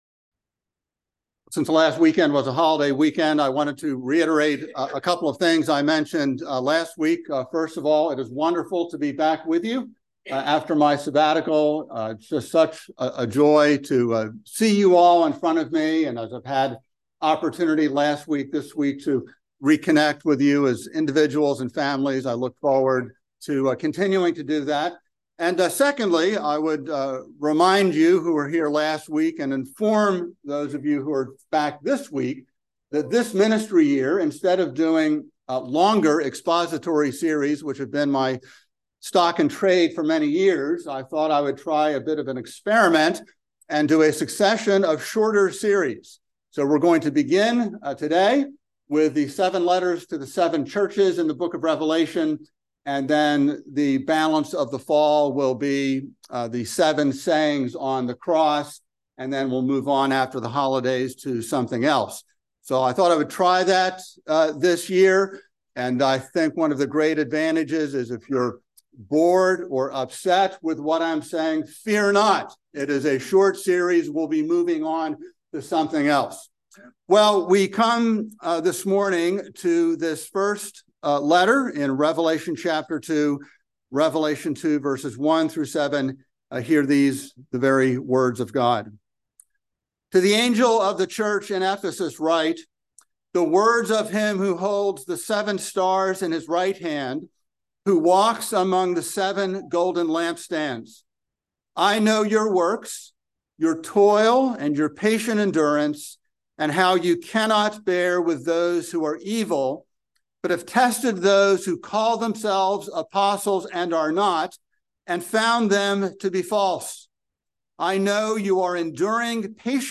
by Trinity Presbyterian Church | Sep 11, 2023 | Sermon